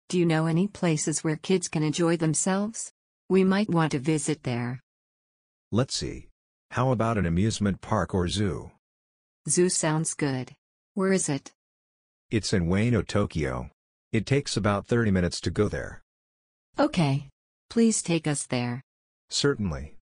[英語ダイアログ:English]